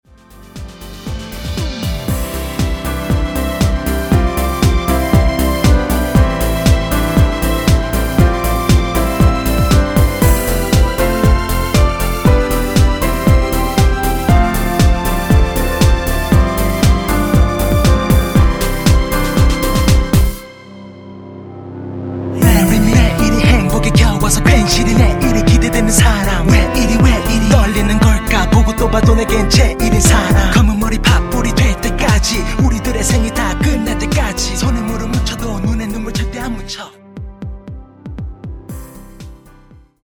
전주가 없는 곡이라 2마디 전주 만들어 놓았습니다.(원키 멜로디MR 미리듣기 참조)
원키에서(-2)내린 랩과 멜로디 포함된 MR입니다.(미리듣기 확인)
앞부분30초, 뒷부분30초씩 편집해서 올려 드리고 있습니다.
중간에 음이 끈어지고 다시 나오는 이유는